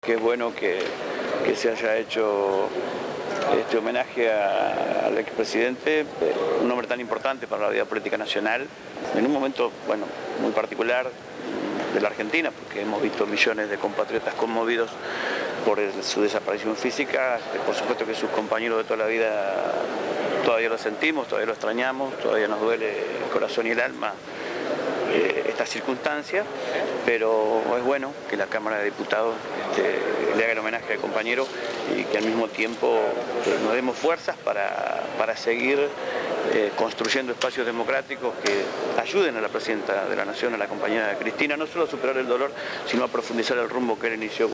Entrevistado